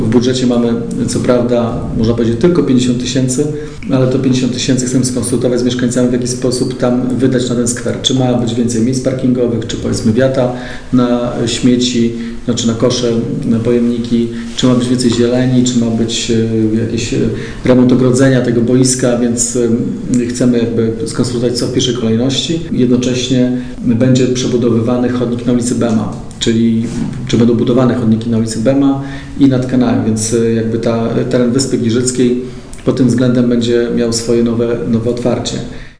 Mówi burmistrz Giżycka Wojciech Iwaszkiewicz: